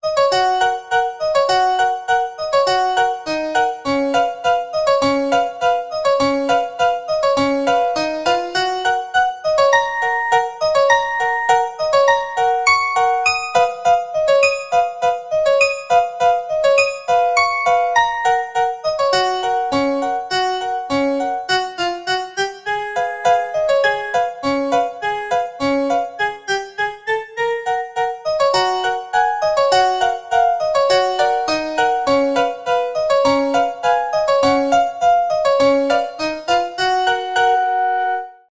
童謡